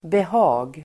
Uttal: [beh'a:g]